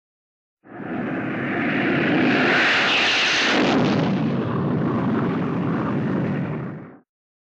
BSG FX - Viper - Peel off
BSG_FX_-_Viper_-_Peel_off.wav